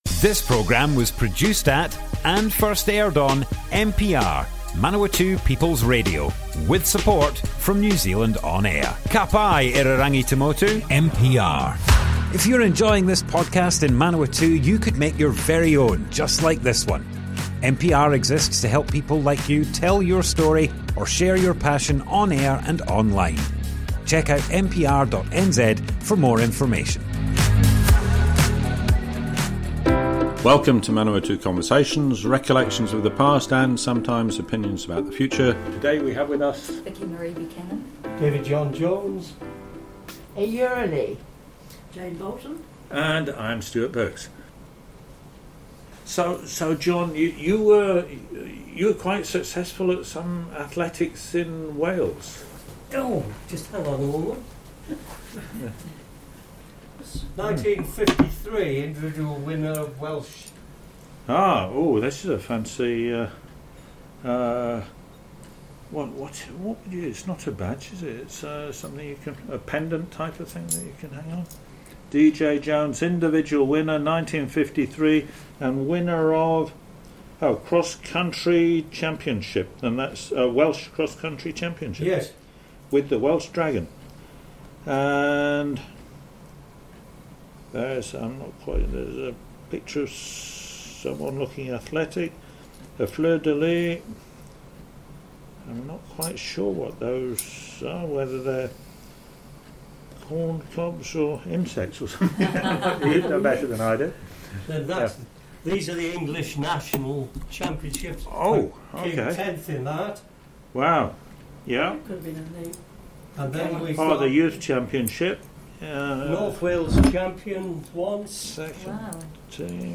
Manawatu Conversations Object type Audio More Info → Description Broadcast on Manawatu People's Radio, 20th September 2022.
oral history